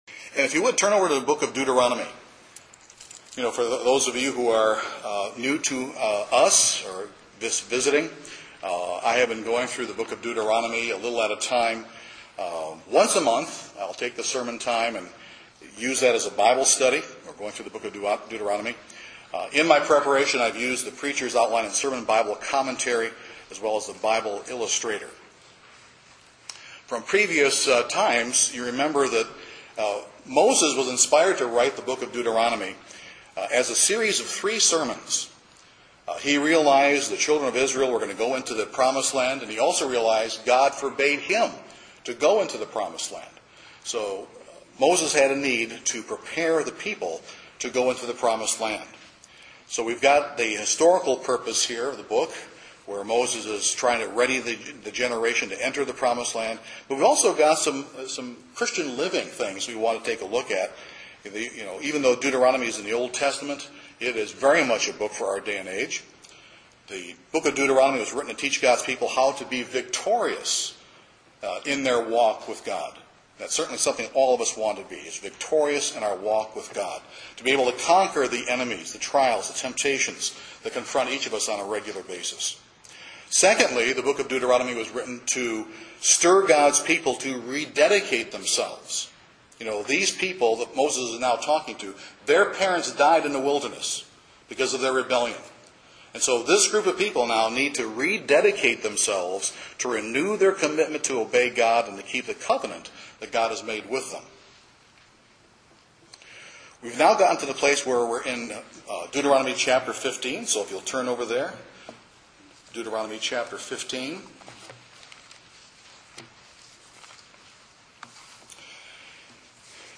Deuteronomy 15 discusses the year of release in ancient Israel. However, what modern day lessons are there to be learned for the Christian? This sermon will cover that material.